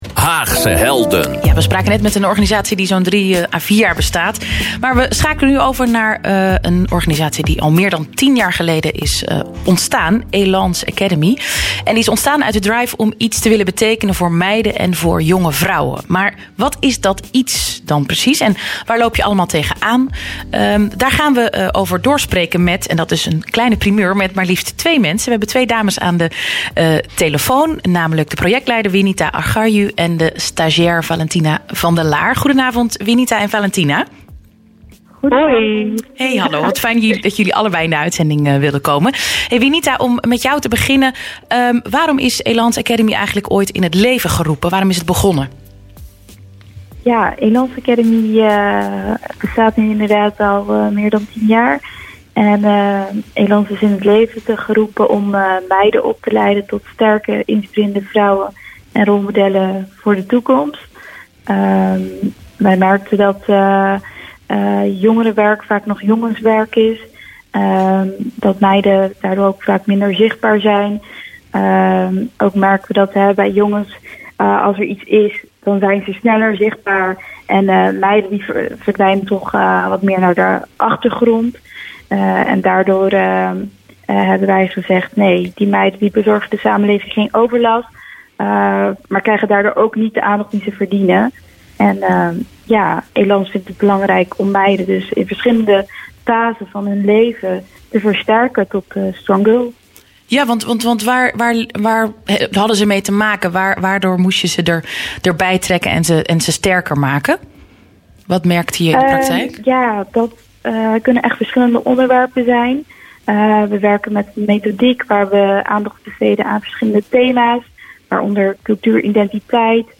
Ben je benieuwd naar wat er verteld wordt in het interview?